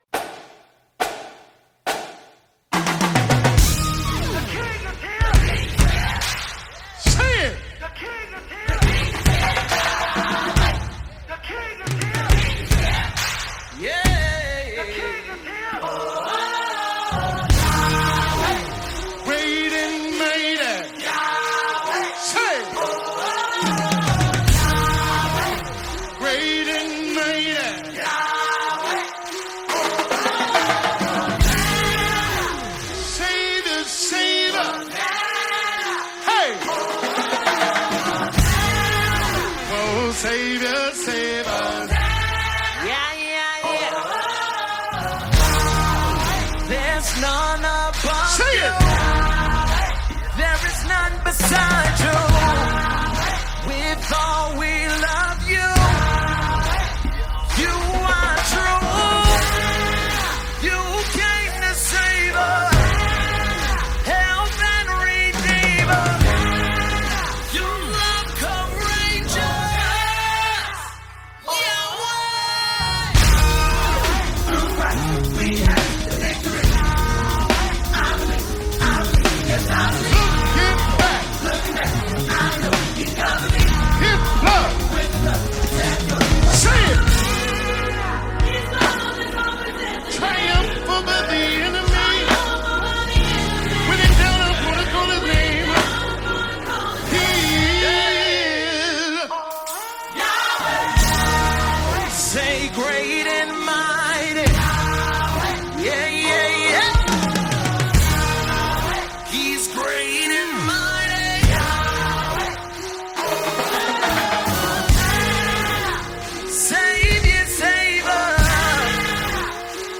Foreign Artists - Gospel Songs Collection
it is a divine symphony of worship.